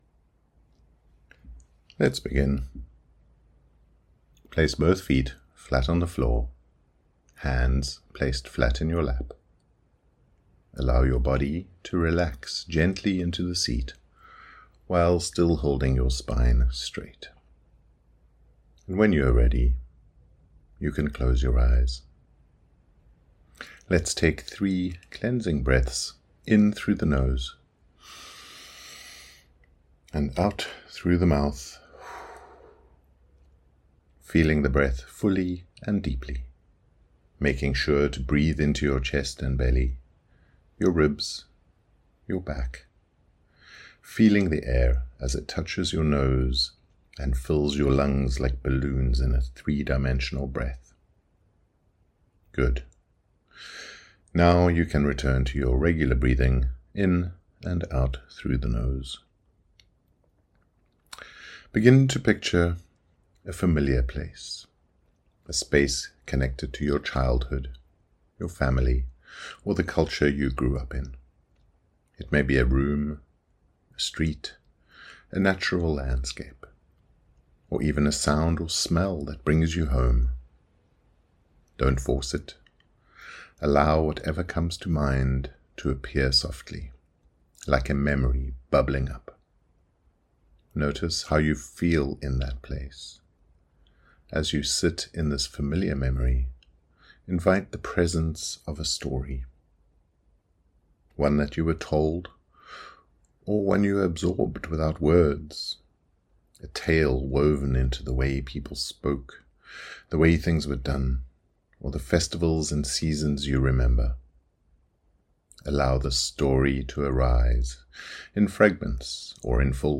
Theatre of Tales Meditation
WS40-meditation-theatre-of-tales.mp3